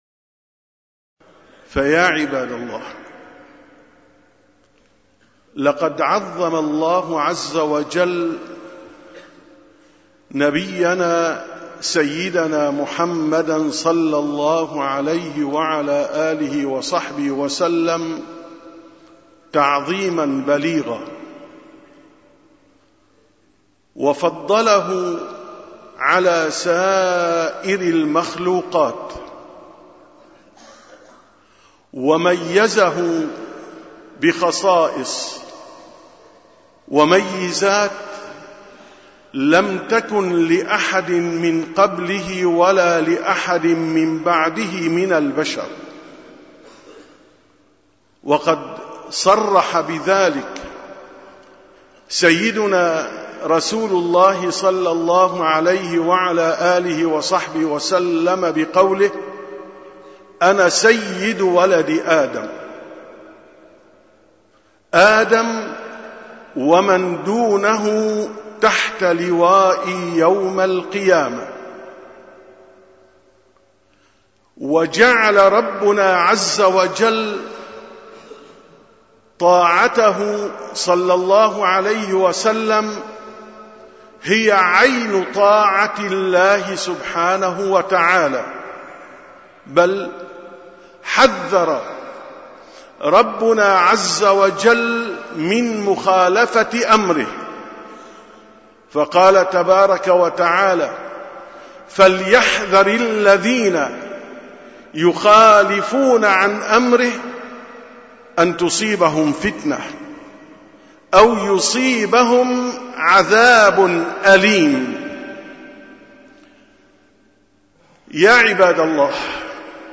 930ـ خطبة الجمعة: غرس الله تعالى محبته صلى الله عليه وسلم في الجمادات وغيرها